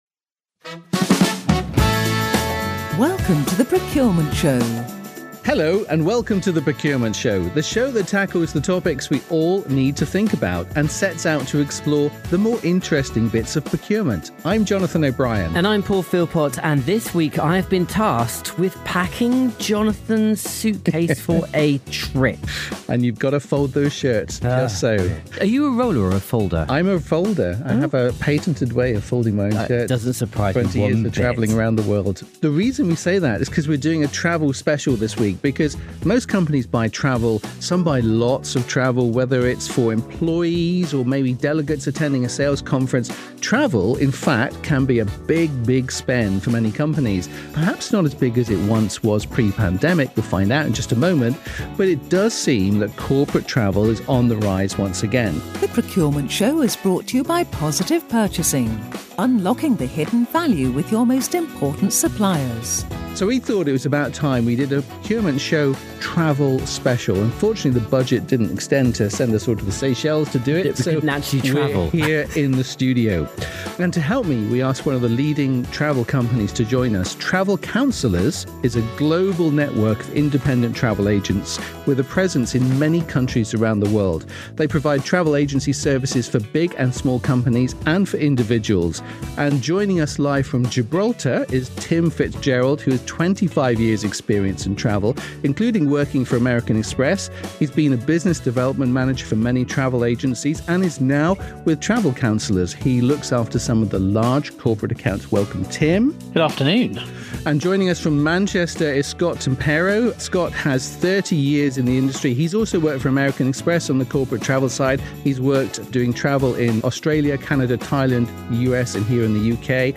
With businesses getting back on the trains, ‘planes and automobiles post-Covid, it’s important for everyone to get the best deal when it comes to sending employees to conferences and meetings. To give us the best advice, we’ve got three experts on hand